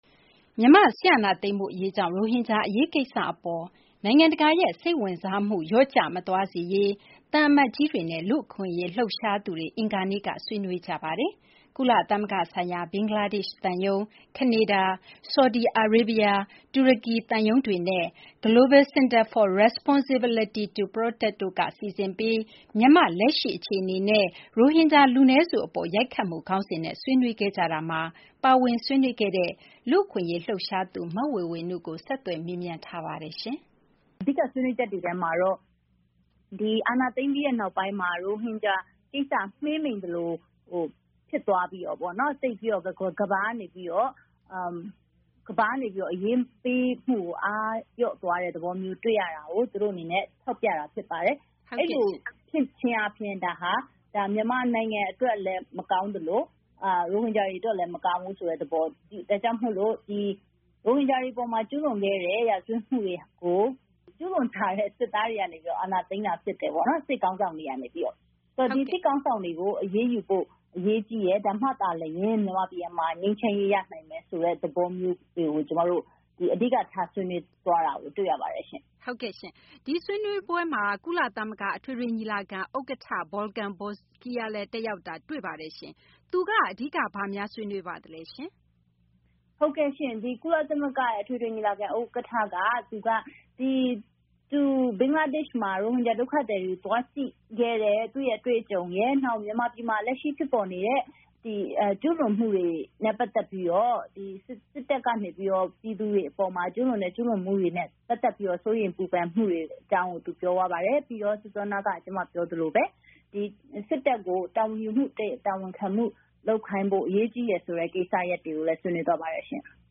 စစ်အာဏာသိမ်းမှုကြောင့် ရိုဟင်ဂျာအရေး မပေါ့လျော့စေဖို့ ကုလဆိုင်ရာ သံအမတ်တချို့ ဆွေးနွေးချက် (တက်ရောက်ခဲ့သူ မဝေဝေနု နှင့် မေးမြန်းခန်း)